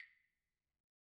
Claves1_Hit_v1_rr1_Sum.wav